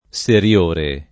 SerL1re o SerL0re] agg. — preval. in Tosc. e nell’It. mediana, più nettam. che nell’opposto recenziore, la pn. chiusa dell’-o- (sull’analogia di migliore, peggiore, ecc.) rispetto alla pn. aperta (regolare per principio in voci dòtte)